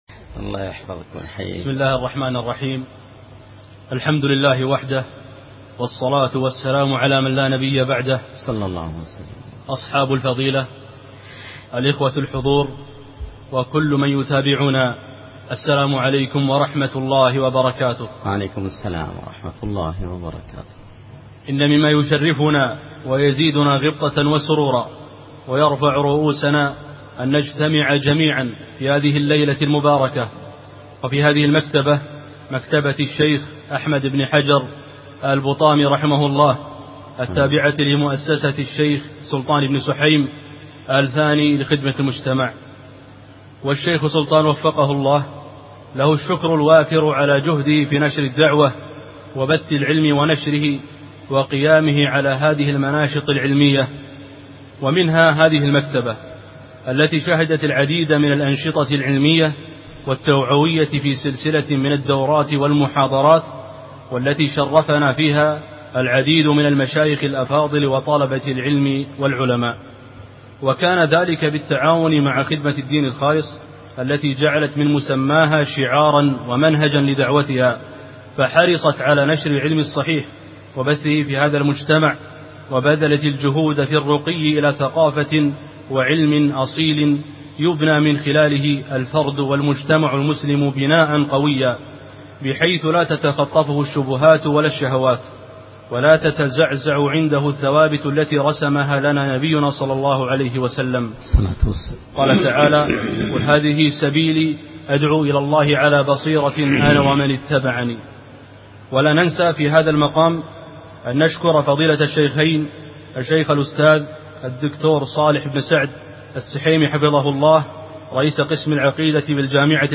الإرهاب أسبابه وعلاجه وموقف المسلم من الفتن - محاضرة